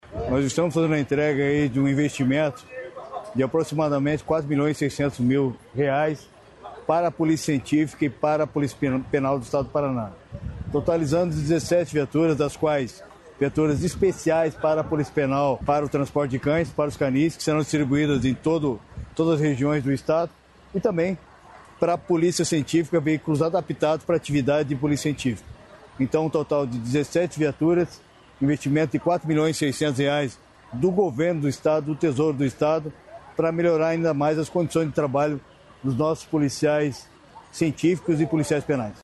Sonora do secretário da Segurança Pública, Hudson Teixeira, sobre os investimentos para reforçar as polícias